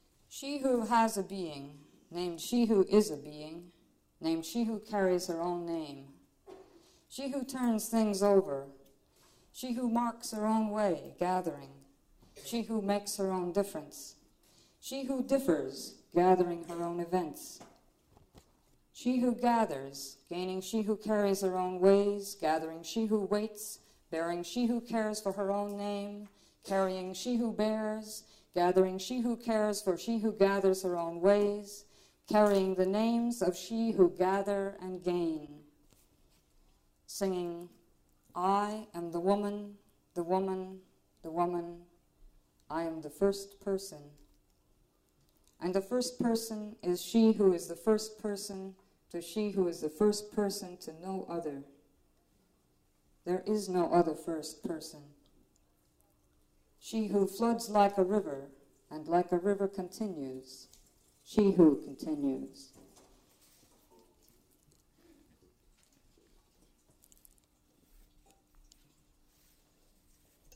Poetry, Live Performance
A poetry reading featuring Judy Grahn. Grahn reads from "She Who" and other works.
1/4 inch audio tape